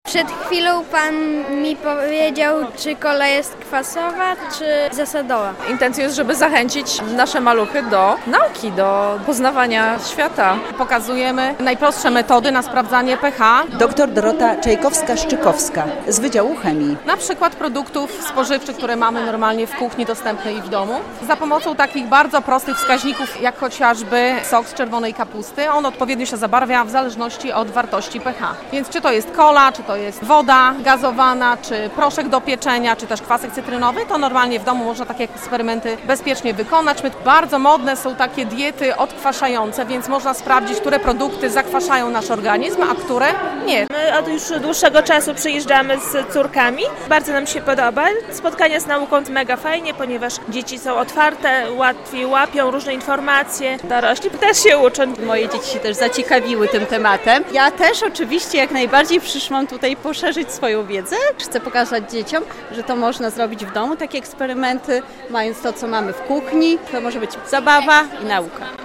Spotkania z nauką - relacja